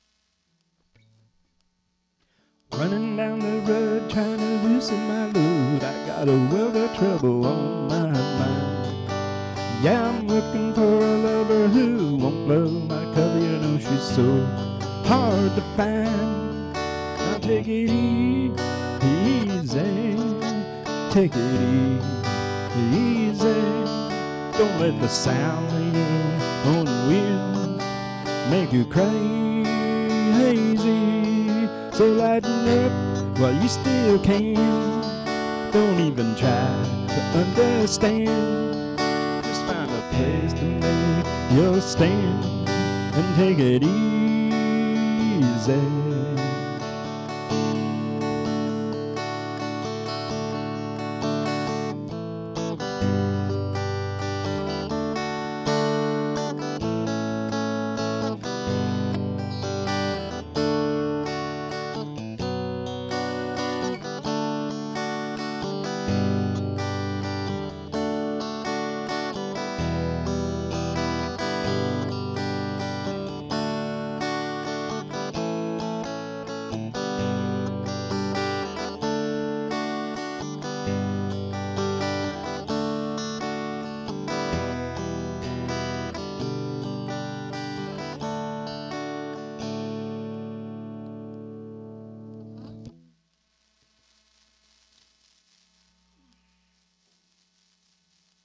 I play guitar (can cover on bass) and keyboards.
Reasonably good on vocals.